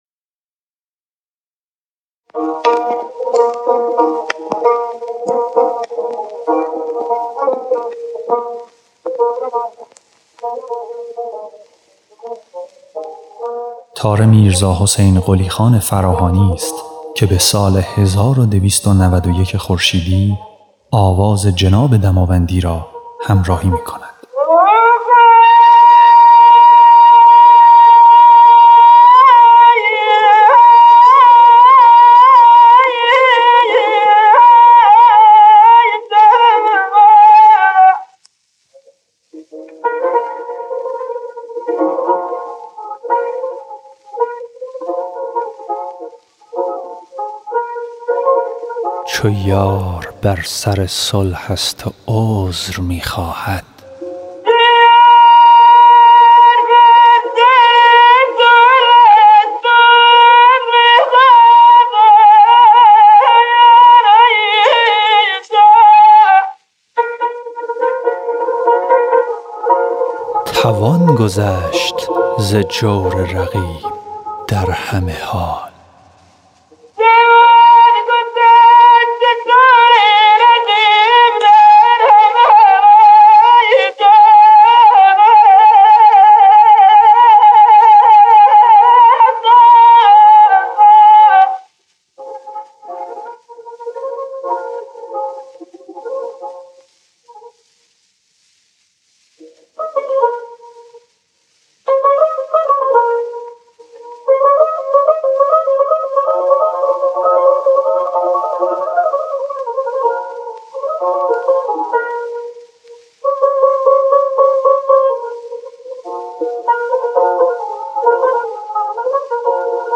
ضبط سری اول آثار
خواننده
نوازنده تار